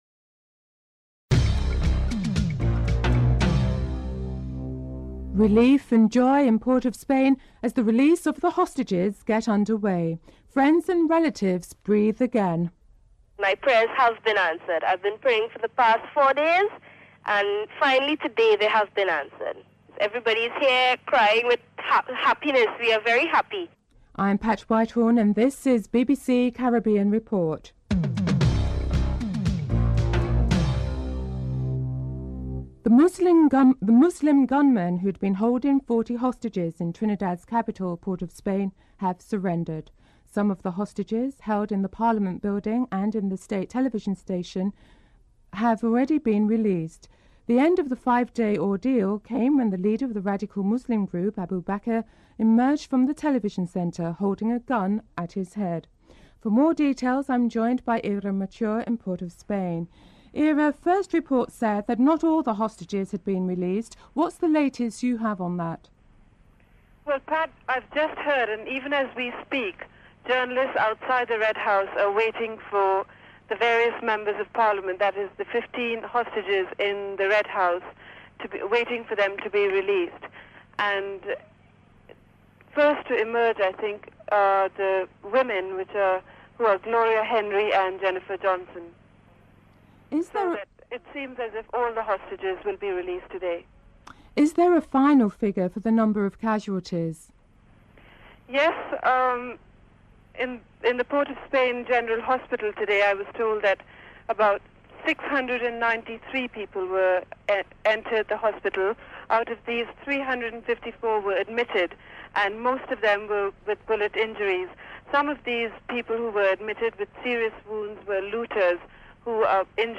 Kate Adie (correspondent) describes in detail the surrender of the fifty insurgents who were later driven away in army vehicles.
4. Katie Adie (correspondent) reports on the surrender of the insurgents in Port of Spain (03:03-04:32)
6. Reactions of Trinidadians on the punishment that should be meted out to the insurgents (06:11-07:25)
9. Interview with Trinidadians on the implications of the coup with regards to the economy (13:15-15:00)